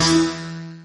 Descarga de Sonidos mp3 Gratis: sintetizador 14.